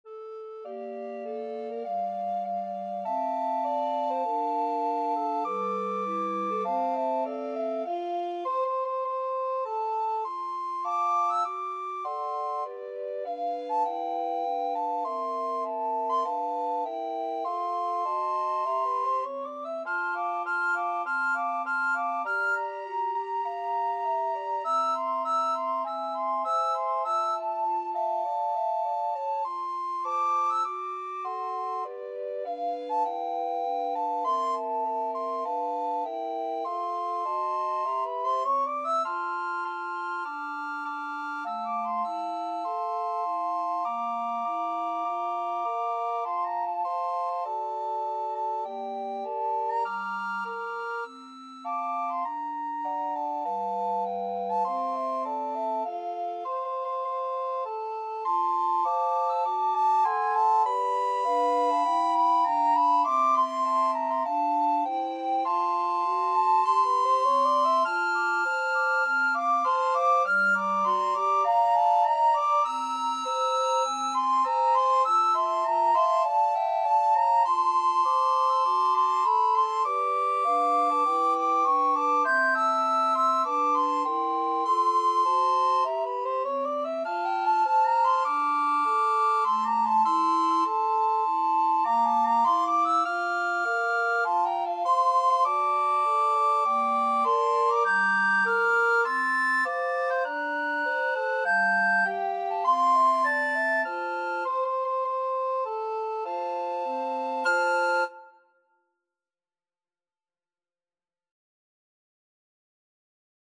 Soprano RecorderAlto RecorderTenor RecorderBass Recorder
4/4 (View more 4/4 Music)
Moderato = c. 100
Jazz (View more Jazz Recorder Quartet Music)
Rock and pop (View more Rock and pop Recorder Quartet Music)